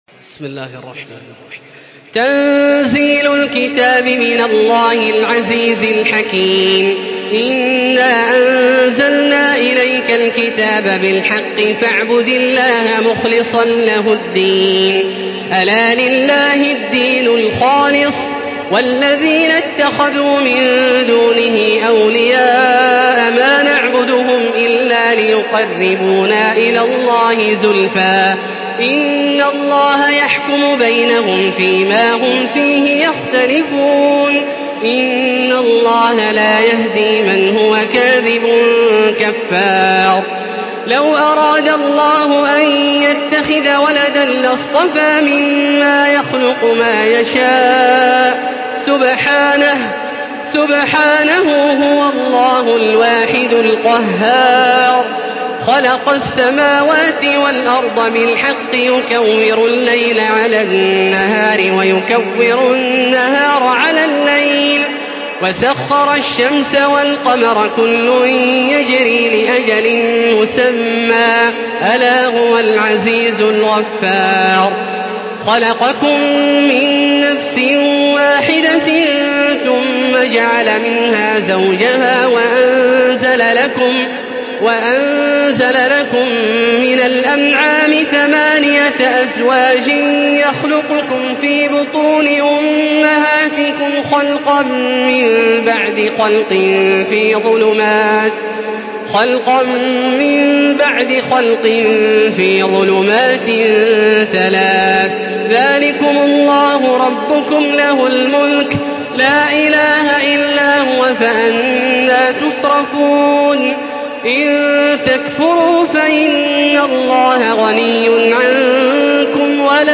Surah Az-zumar MP3 in the Voice of Makkah Taraweeh 1435 in Hafs Narration
Murattal